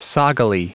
Prononciation du mot soggily en anglais (fichier audio)
Prononciation du mot : soggily